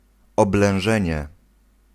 Ääntäminen
US : IPA : [sidʒ]